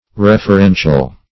Referential \Ref`er*en"tial\ (-shal), a.